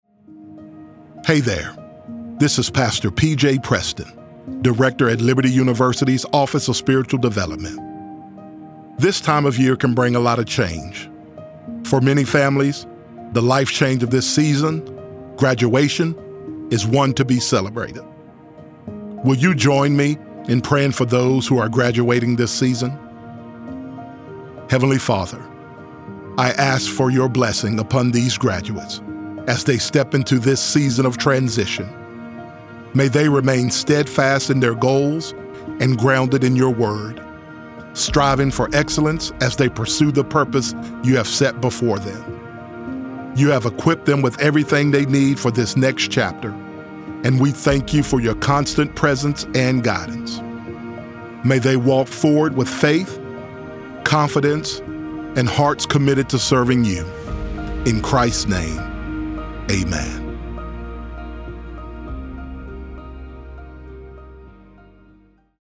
Prayer for College Grads 2